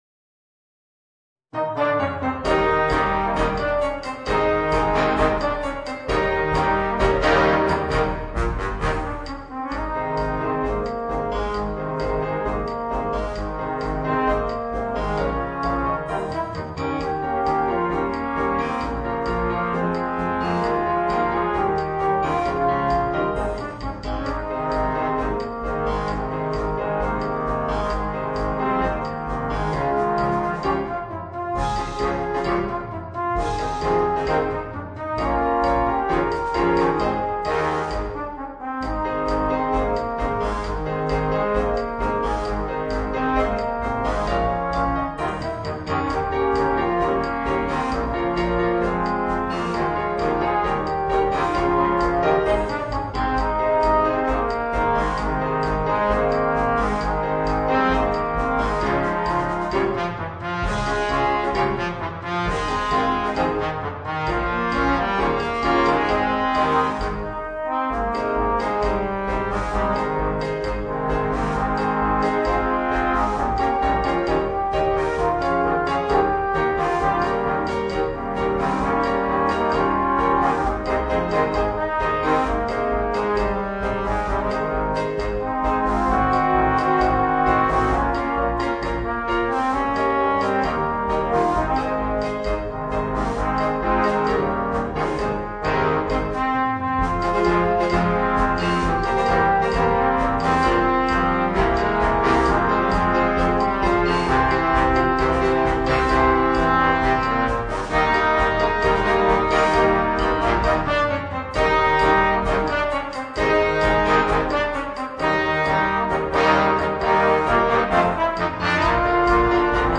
Voicing: 4 Trombones and Piano